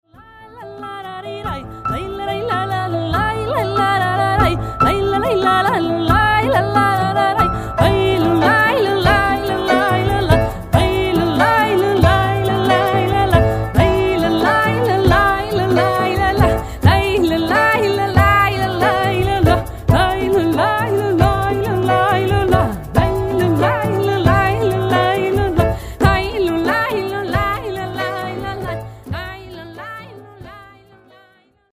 cello, voice, and percussion
transverse flute and percussion